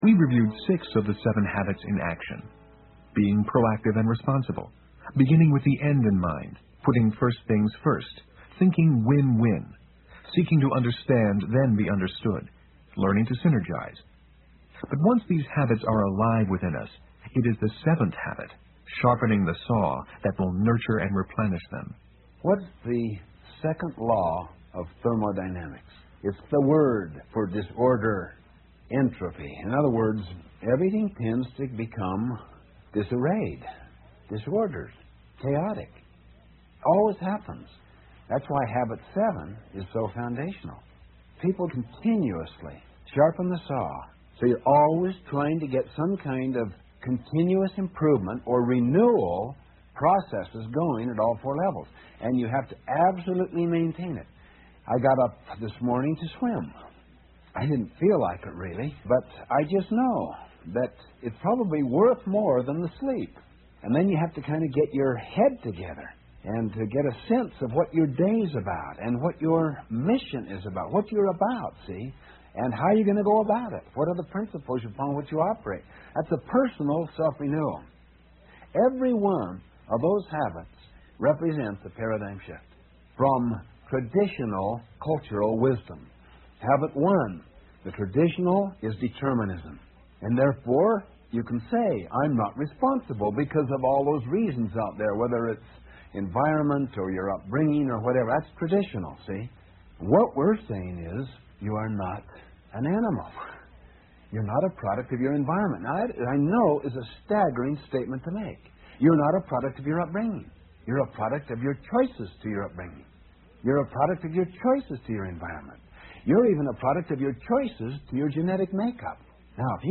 有声畅销书：与成功有约22 听力文件下载—在线英语听力室